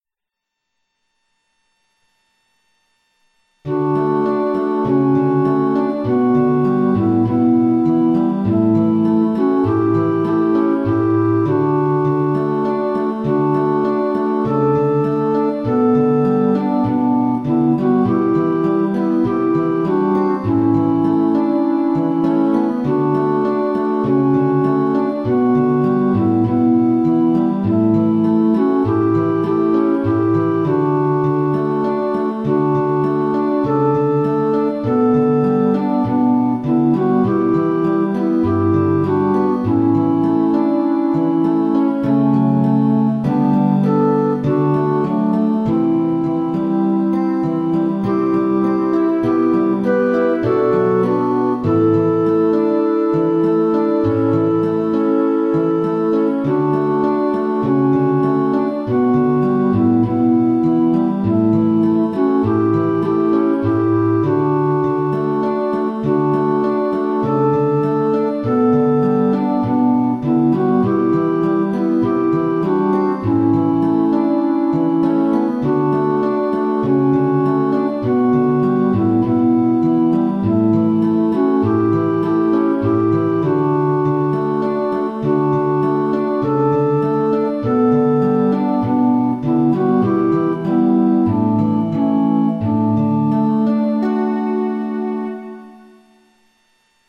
A Psalm of quiet devotion to God's Word.